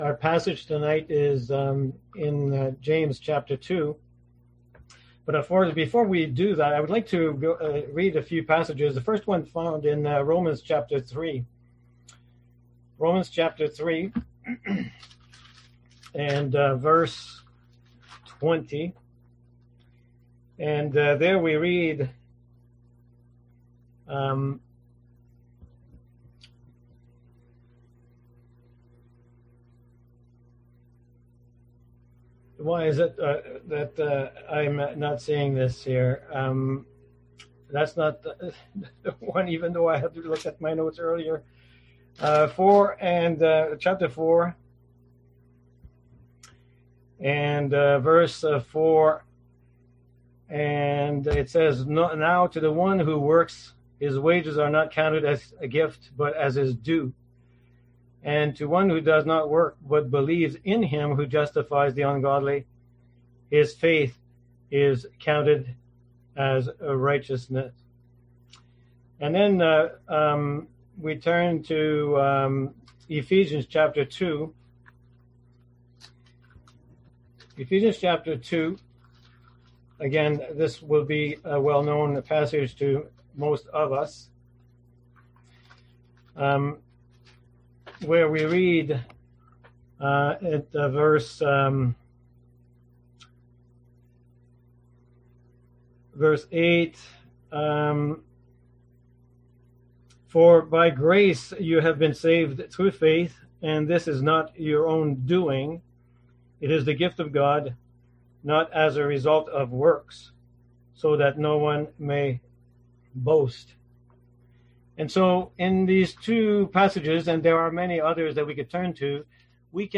Service Type: Seminar Topics: Faith , Salvation , Works